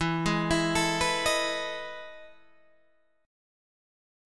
E Major 11th